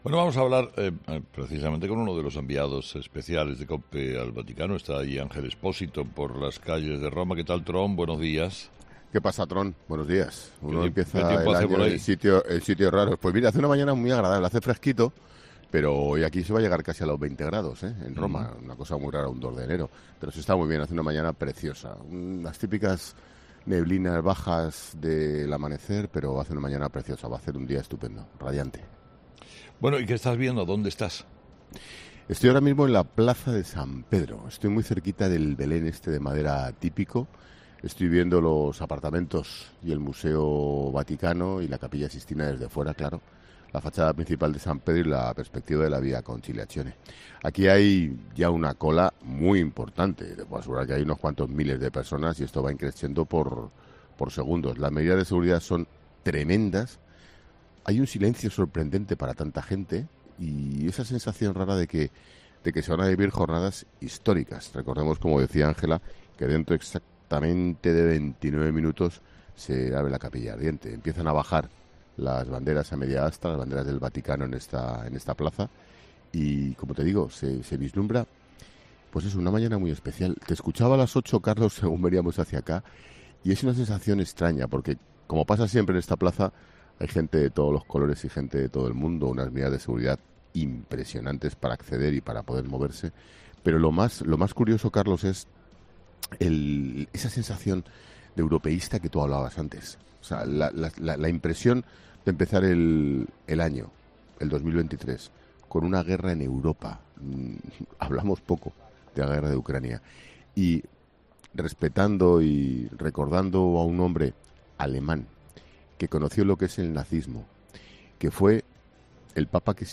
Expósito, desde la Plaza de San Pedro: "Hay un enorme silencio pese a los miles de fieles que esperan"
Desde una Roma que ha amanecido con frío y neblina, que acoge a las miles de personas que quieren despedir al Papa emérito, al lado del Belén del Vaticano, Expósito ha trasladado a los oyentes de Herrera en COPE, el silencio que reina en la Plaza de San Pedro pese a que se acumulan miles de personas y esa sensación de que se van a vivir jornadas históricas.